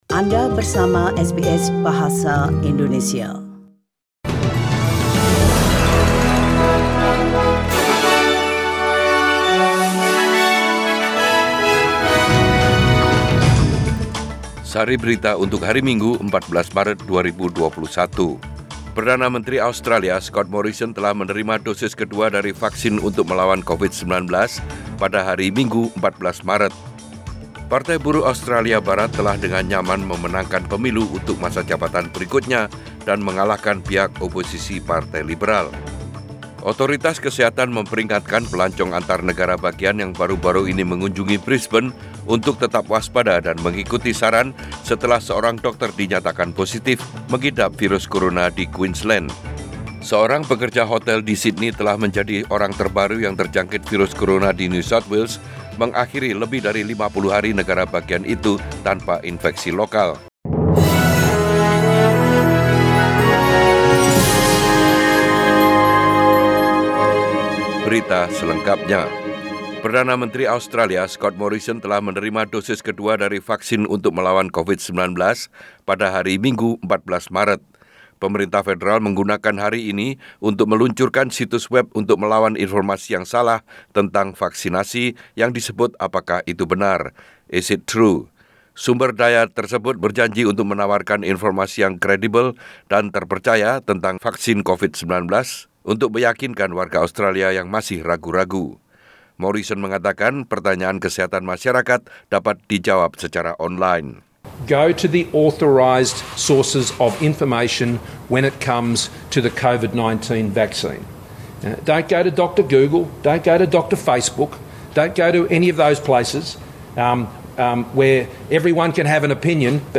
SBS Radio News in Bahasa Indonesia - 14 March 2021